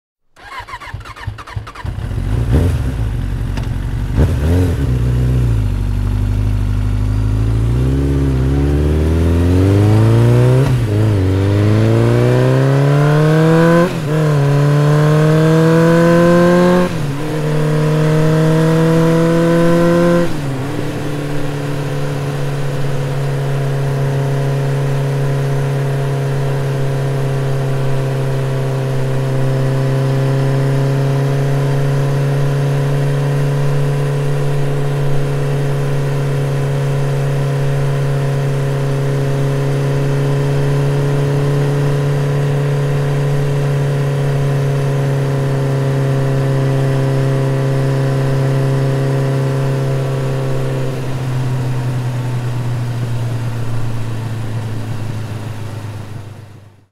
Suara Motor MP3
Kategori: Suara Kendaraan
Keterangan: Unduh efek suara motor MP3 untuk nada dering WA, semua tipe HP, dan keperluan edit video.
suara-motor-id-www_tiengdong_com.mp3